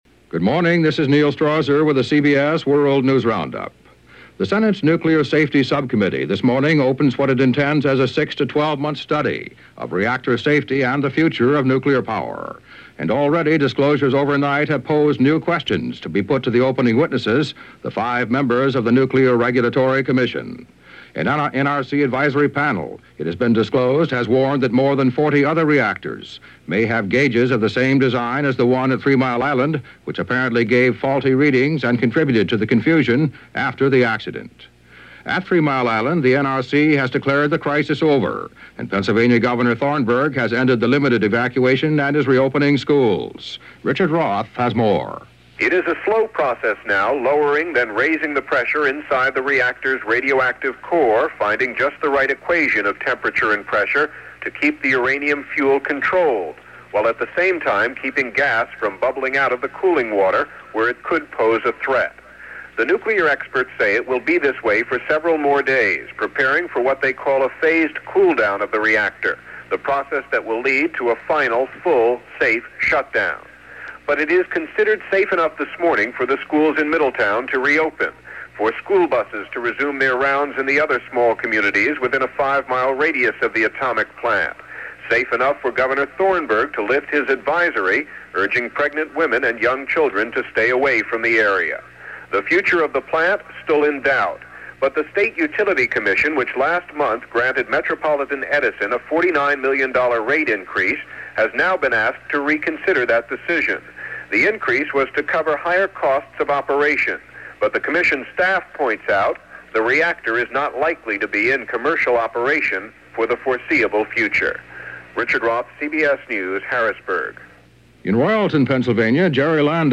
Investigating Three Mile Island: Faulty Gauges - Faulty Logic - April 10, 1979 - news from The CBS World News Roundup